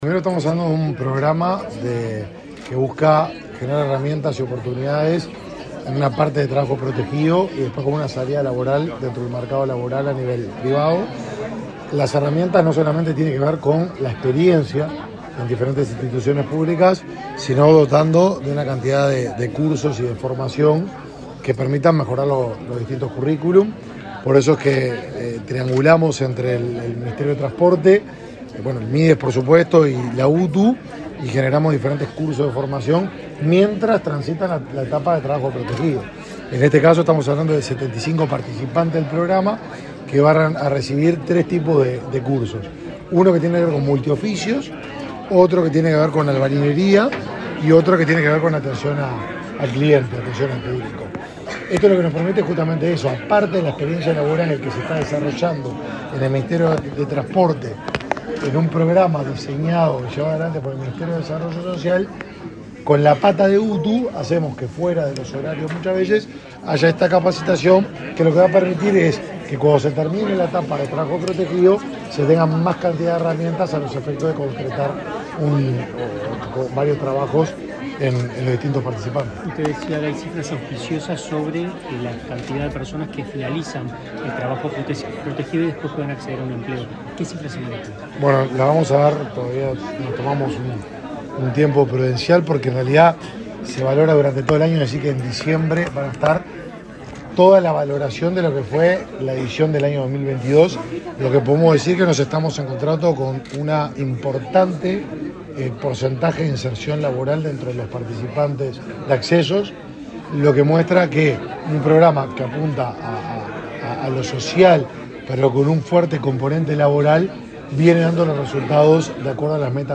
Declaraciones del ministro de Desarrollo Social, Martín Lema
Este jueves 6, el ministro de Desarrollo Social, Martín Lema, dialogó con la prensa luego de participar en la firma de un convenio entre esa cartera,